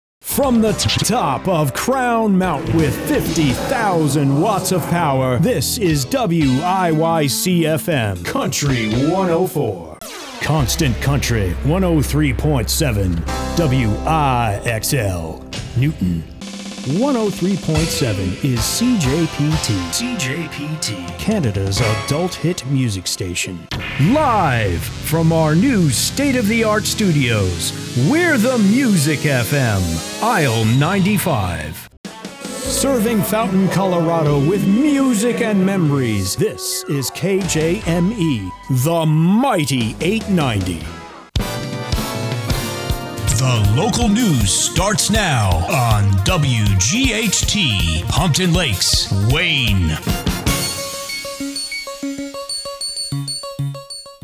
Imaging reel montage --mostly for radio imaging, but some television